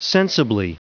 Prononciation du mot sensibly en anglais (fichier audio)
Prononciation du mot : sensibly